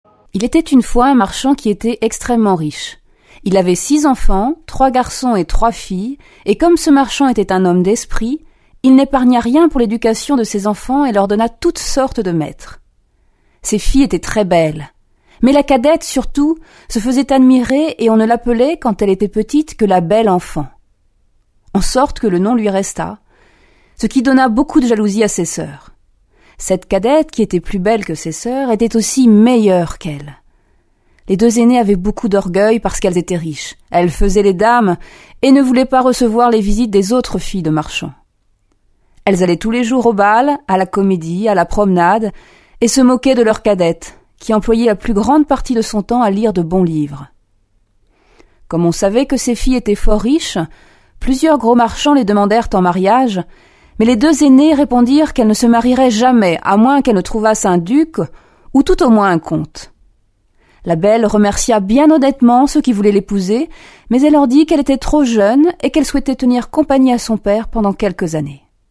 Diffusion distribution ebook et livre audio - Catalogue livres numériques
Musique : Les 4 saisons de Vivaldi